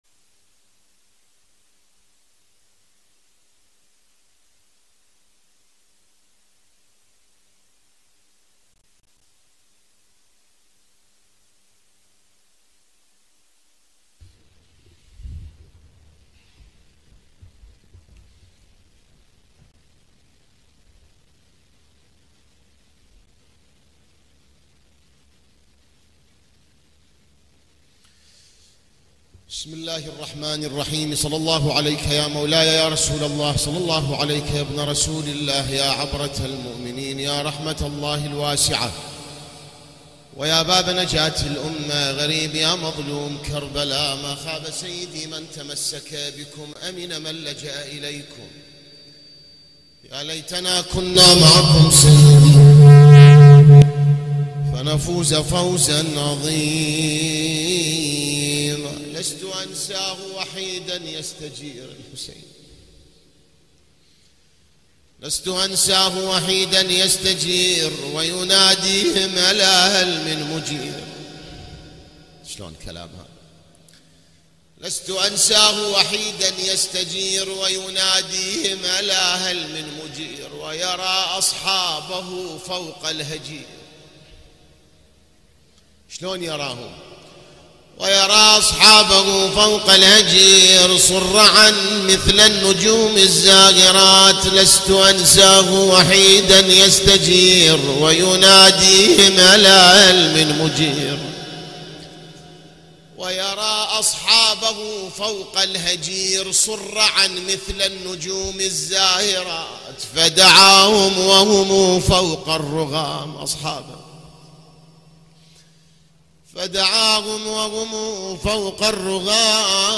ليلة ٦ محرم ١٤٤٦هـ || هيئة الزهراء للعزاء المركزي في النجف الاشرف